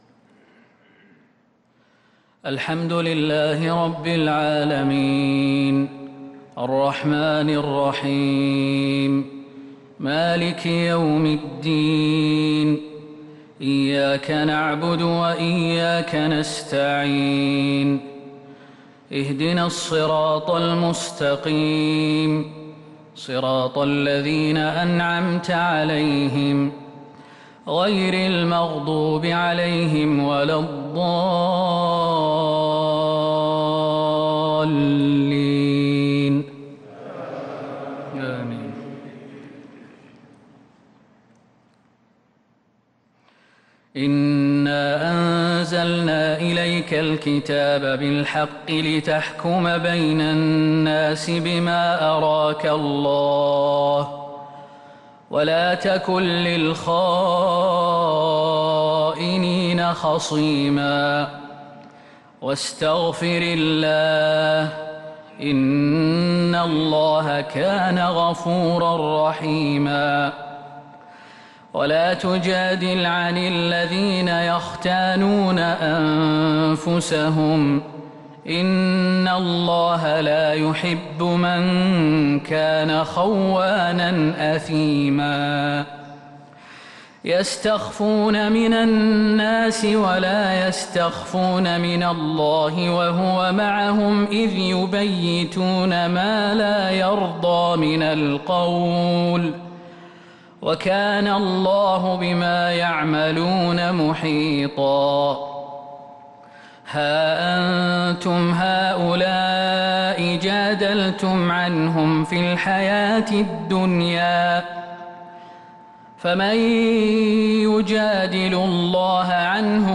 صلاة الفجر للقارئ خالد المهنا 24 رمضان 1443 هـ
تِلَاوَات الْحَرَمَيْن .